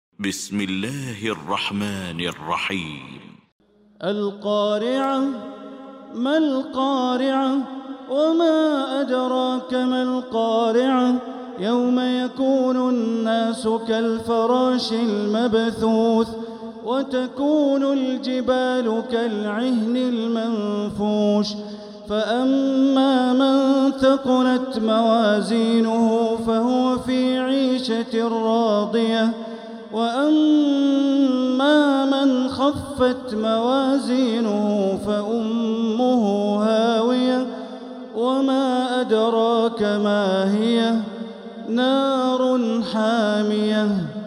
المكان: المسجد الحرام الشيخ: معالي الشيخ أ.د. بندر بليلة معالي الشيخ أ.د. بندر بليلة القارعة